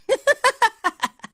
p4u-adachi-laugh.opus